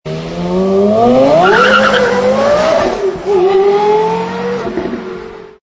Sons de moteurs porsche - Engine sounds porsche - bruit V8 V10 porsche
Carrera GT - accleration(exterieur).mp3